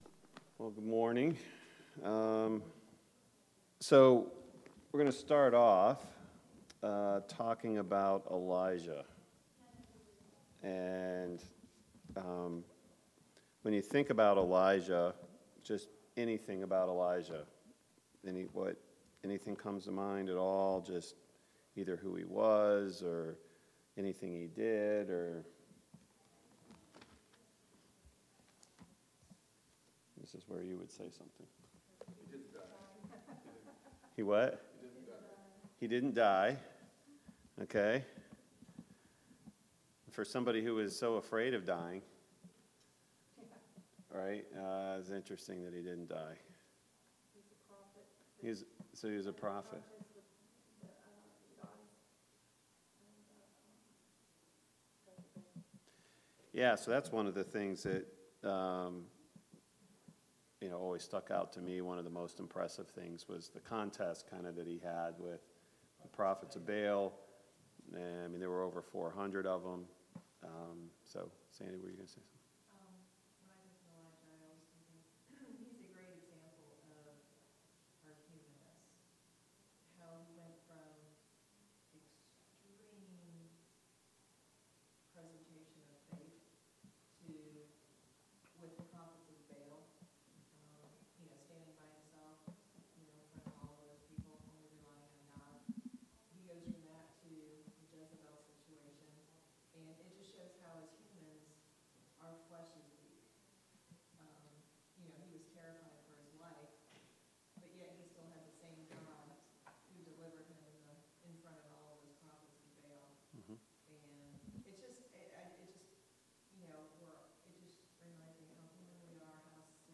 VBS Adult Class